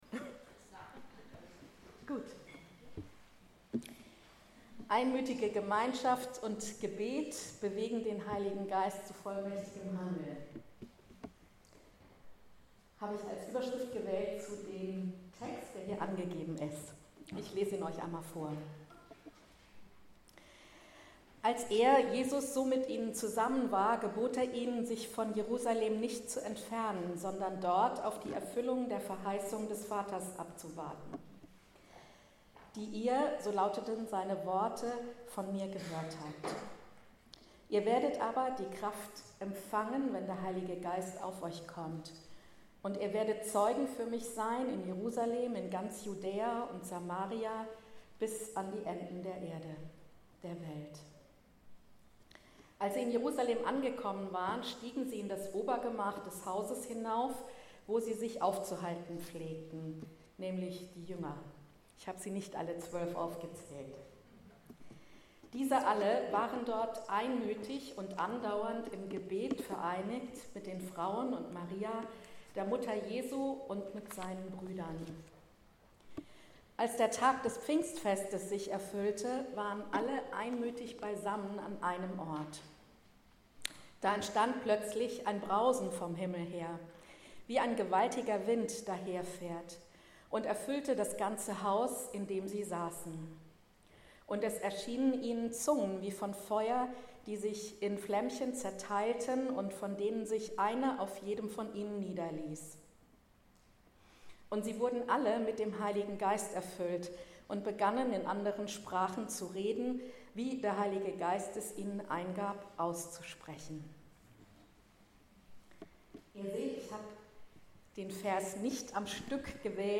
Einmütige Gemeinschaft und anhaltendes Gebet bewegen den Heiligen Geist zu vollmächtigem Handeln ~ Anskar-Kirche Hamburg- Predigten Podcast